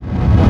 VEC3 Reverse FX
VEC3 FX Reverse 08.wav